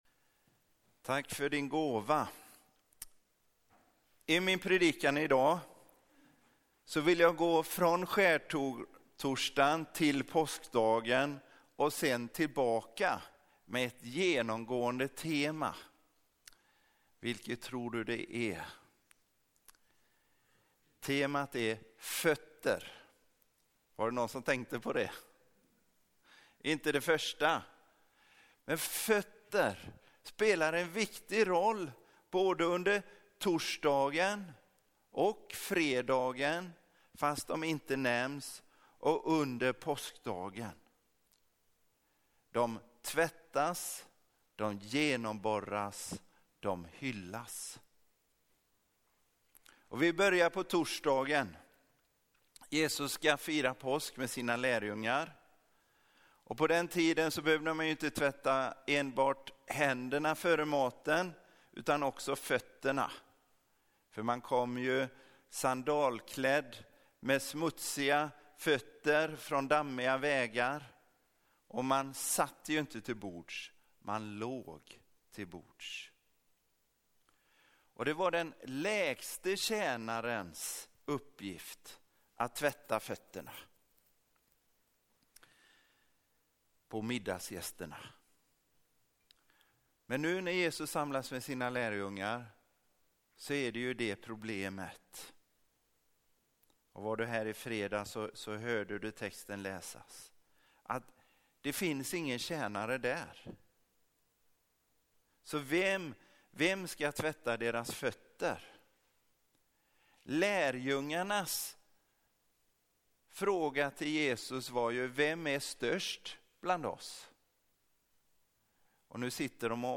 Påskdagspredikan -fötter!
paskdagspredikan-fotter.mp3